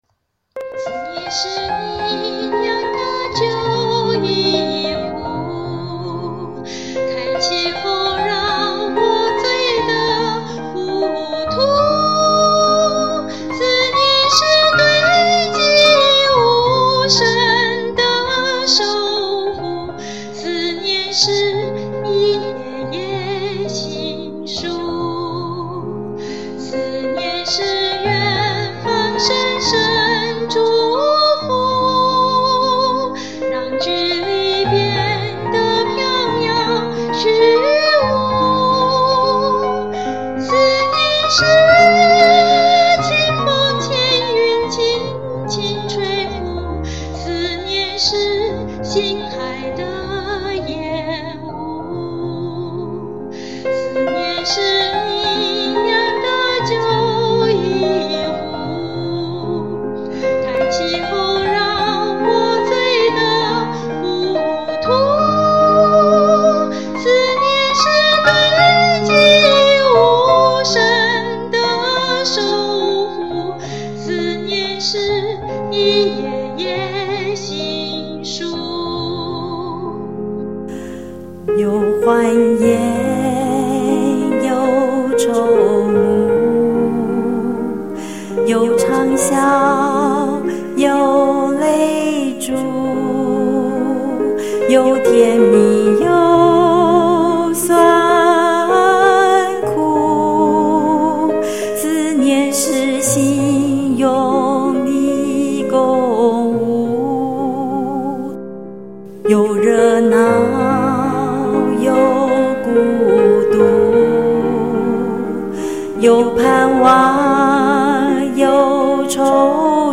试唱 填词 思念